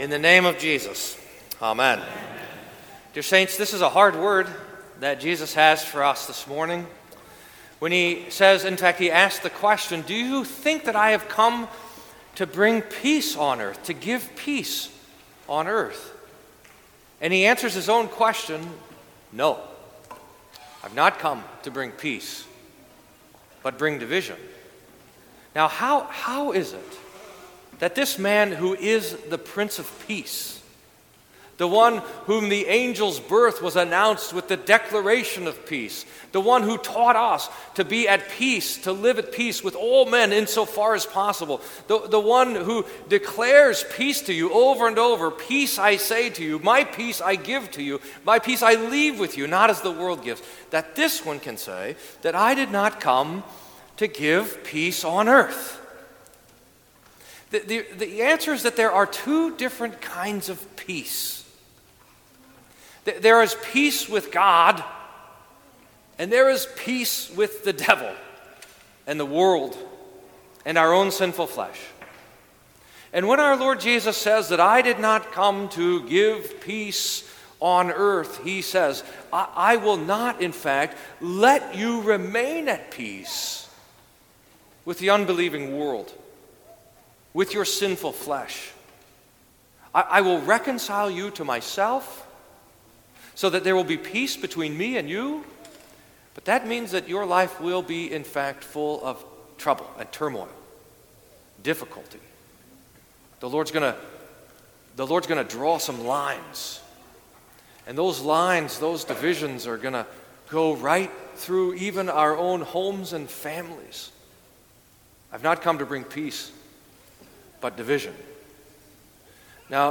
Sermon for Tenth Sunday after Pentecost